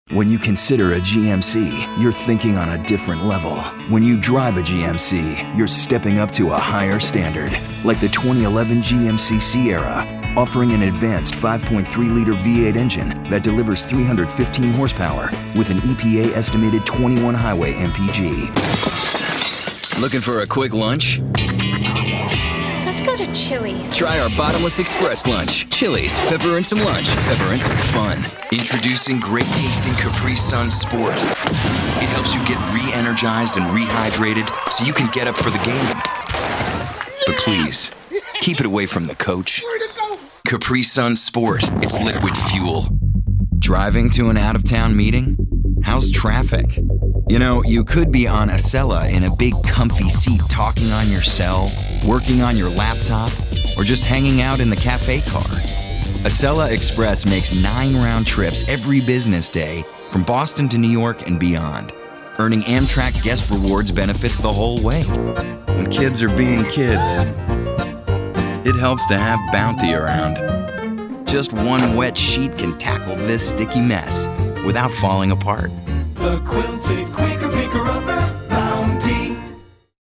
Promo